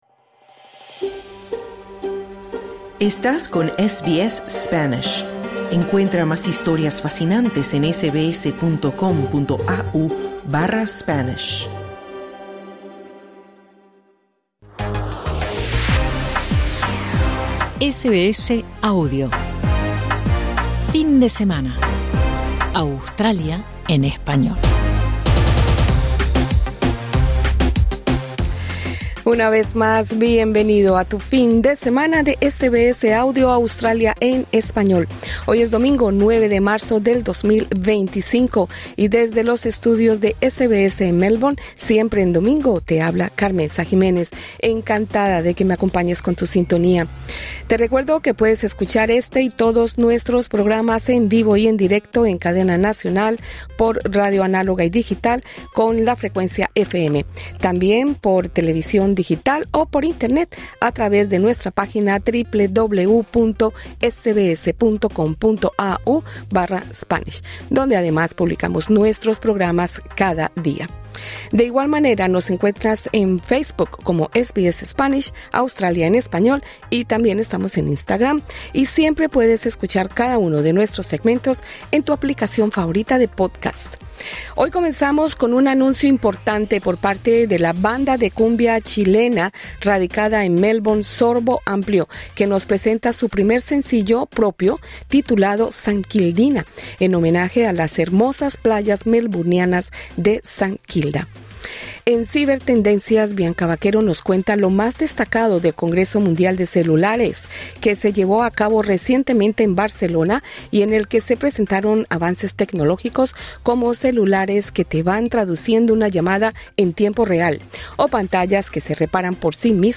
Y, además de nuestro acostumbrado segmento de Cibertendencias, tenemos también una entrevista